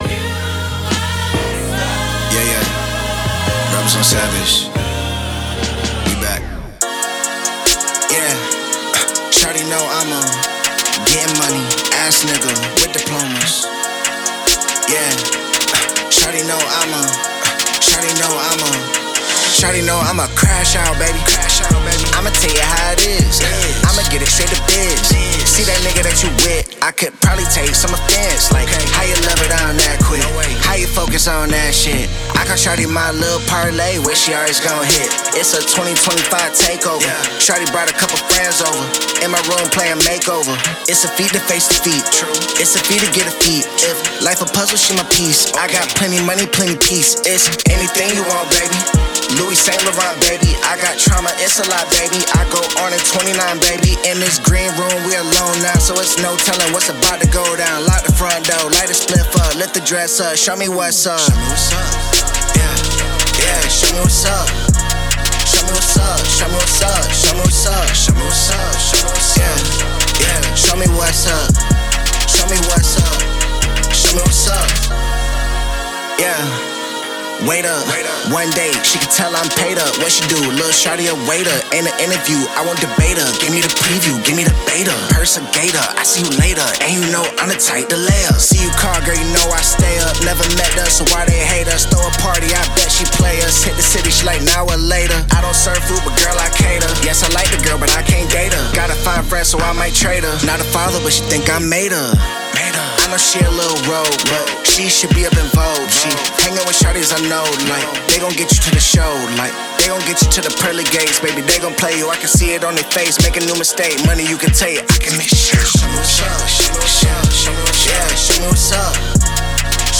Genre: Afrobeat / Street Pop